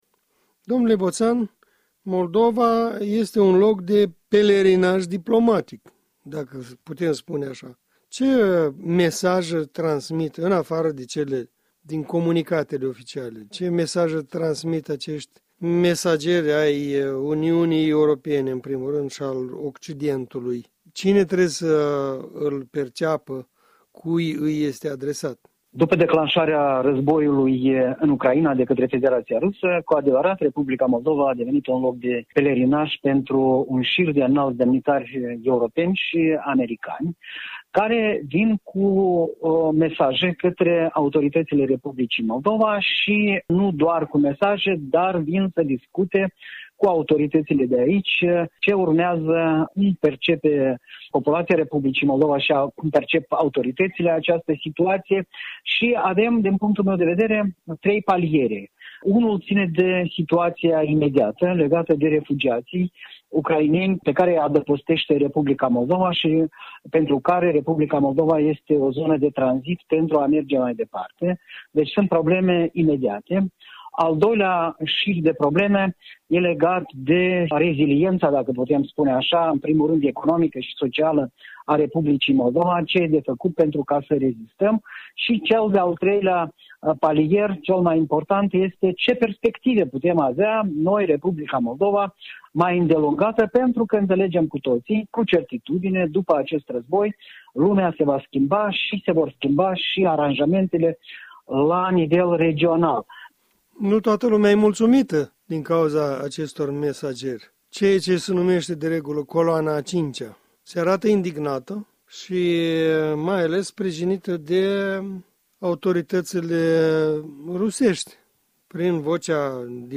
în dialog cu analistul politic